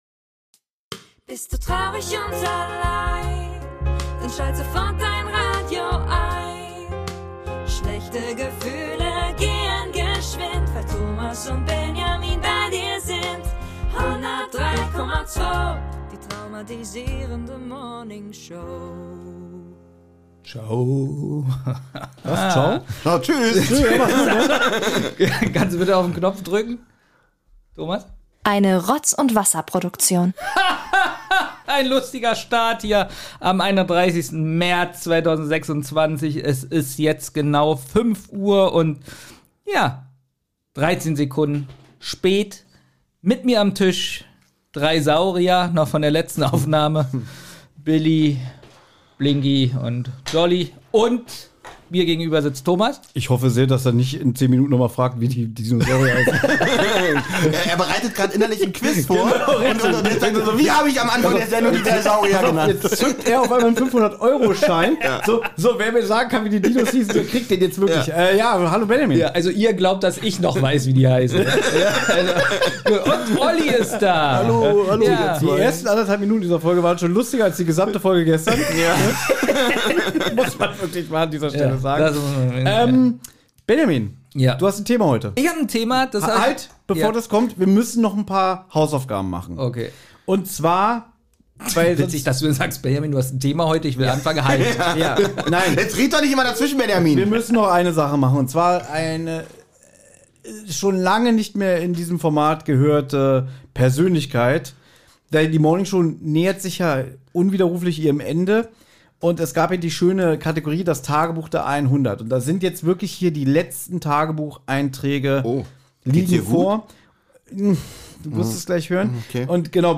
Zu dritt schweifen die Moderatoren ab auf das Thema "Minidisc"